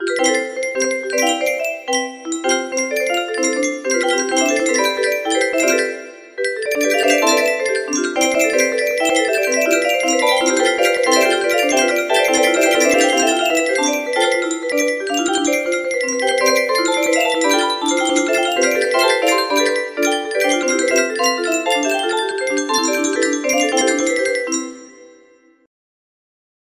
horribly out of tune clone of Pop! Goes the Weasel! music box melody